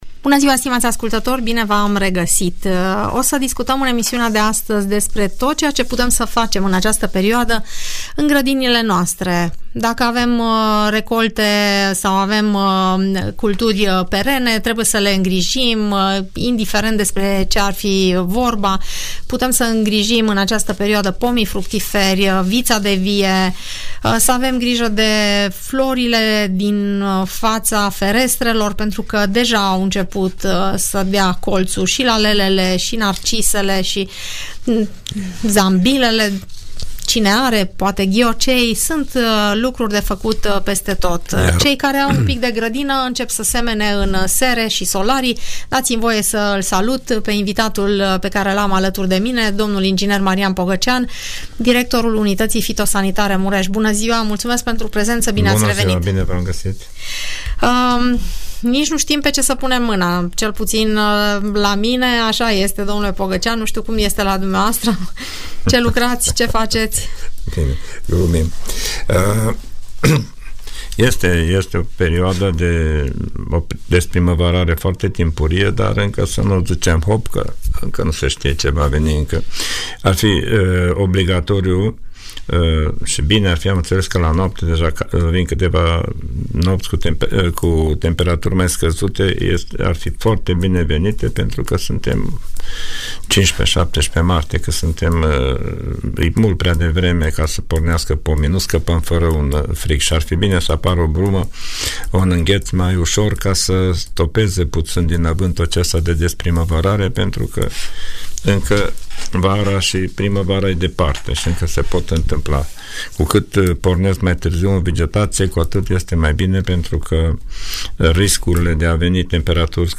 Întrebări și răspunsuri pe teme de agricultură